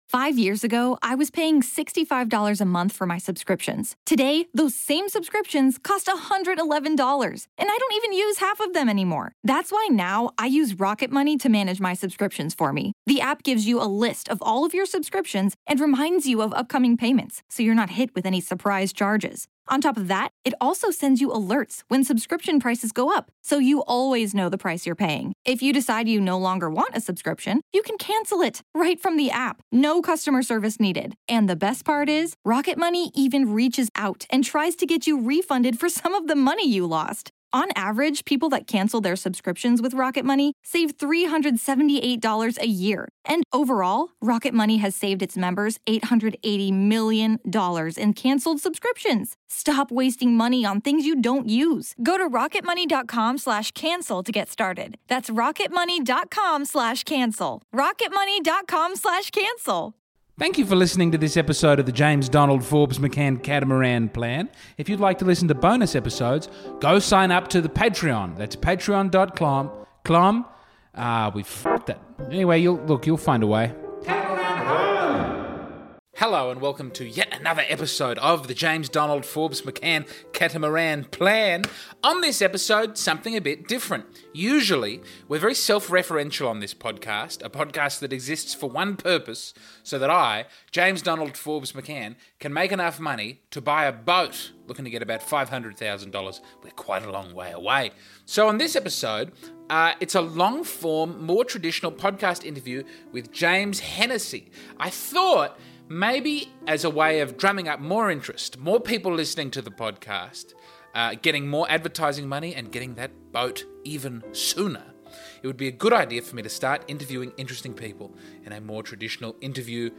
Today we take a break from talking about the boat to see if interviewing interesting people will generate more downloads and more advertising money for said boat.